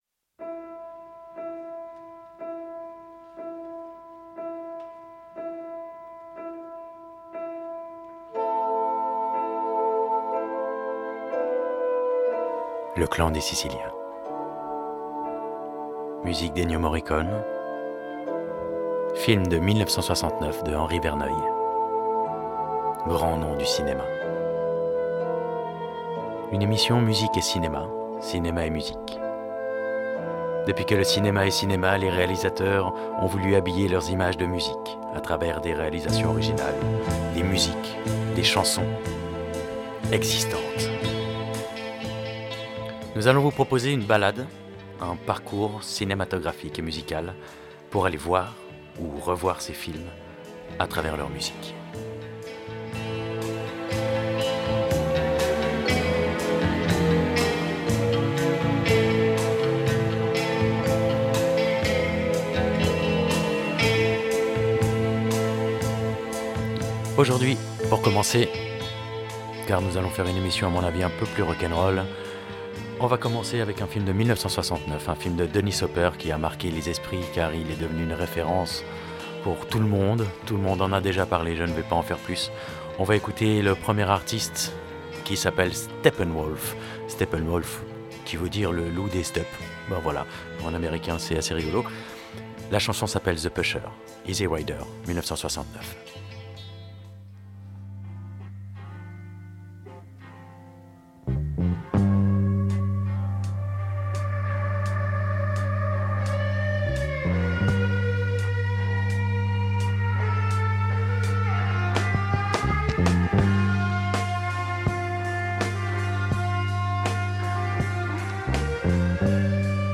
musique et cinéma